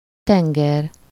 Ääntäminen
Synonyymit océan plan d'eau Ääntäminen France (Paris): IPA: [yn mɛʁ] Tuntematon aksentti: IPA: /mɛʁ/ Haettu sana löytyi näillä lähdekielillä: ranska Käännös Ääninäyte Substantiivit 1. tenger Suku: f .